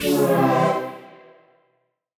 Index of /musicradar/future-rave-samples/Poly Chord Hits/Ramp Down
FR_T-PAD[dwn]-C.wav